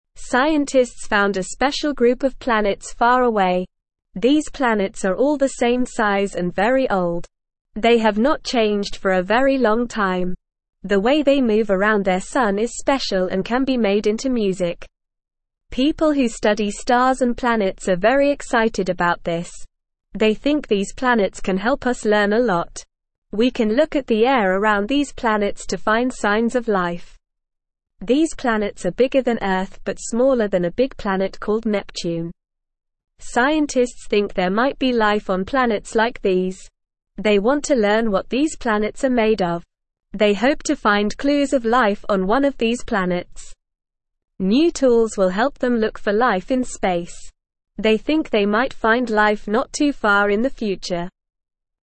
Normal
English-Newsroom-Beginner-NORMAL-Reading-Special-Planets-Music-and-Clues-for-Life.mp3